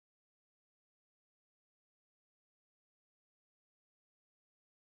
Live from Soundcamp: A foraging-listening walk in Luxembourgh (Audio)